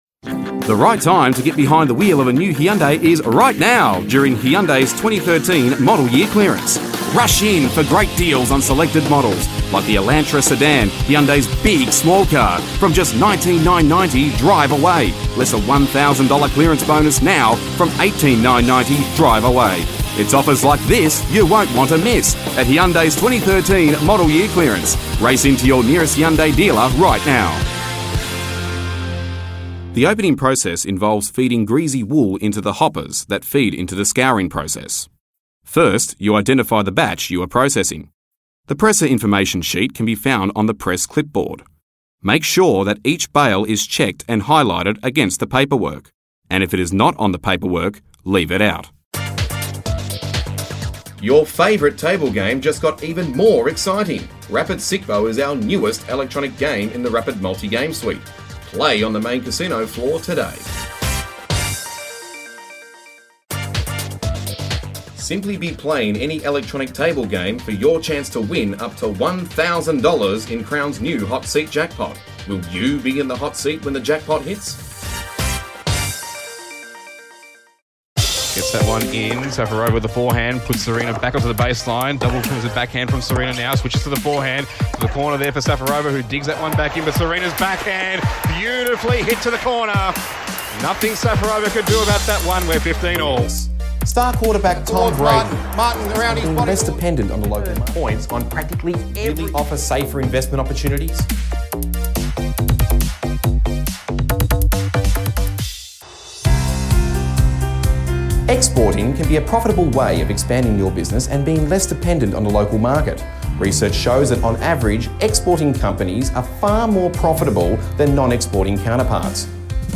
Male
My voice is multi-layered, ranging from deep and meaningful with natural strong projection that helps carry it further with greater volume than other normal voices, to high-pitched and youthful that allows me to pass off as far younger than my actual age.
Radio Commercial And Info Read
Words that describe my voice are Deep, Strong, Projecting.